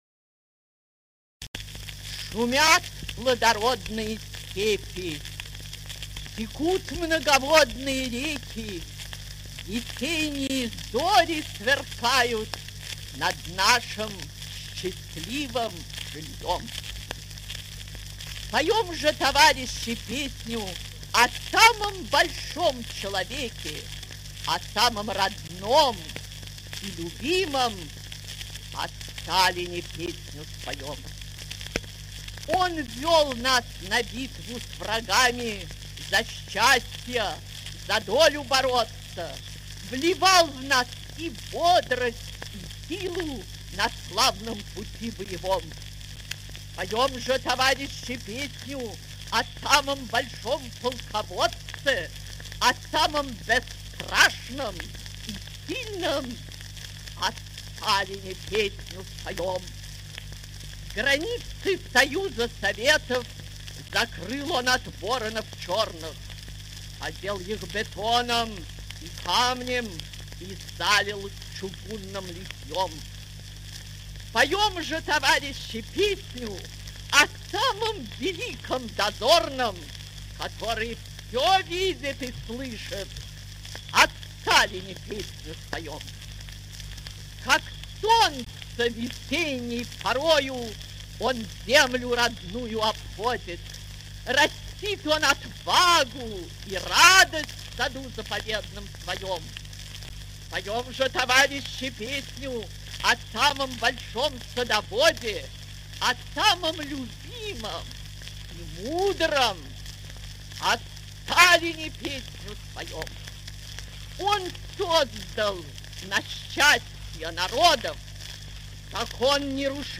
2. «Исаковский Михаил – Песня о Сталине (чит. А.Яблочкина )1937г» /